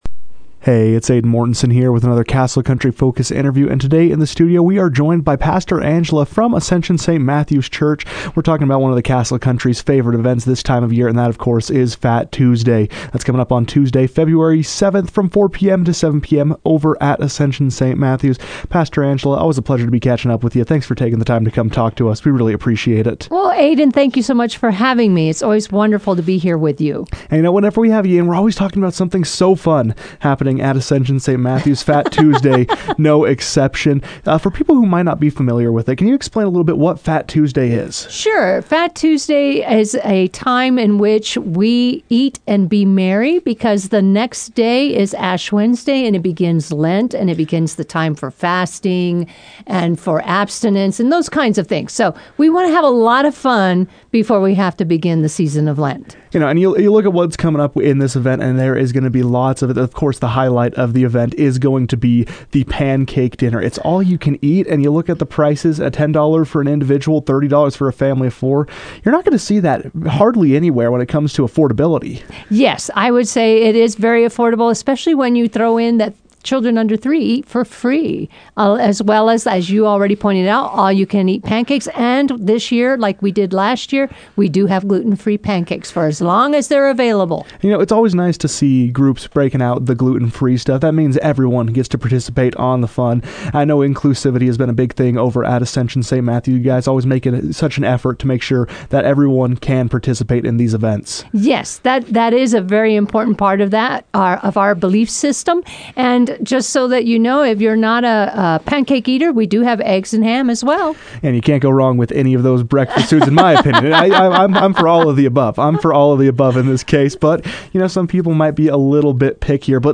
joined the KOAL newsroom to preview the event and what attendees can expect.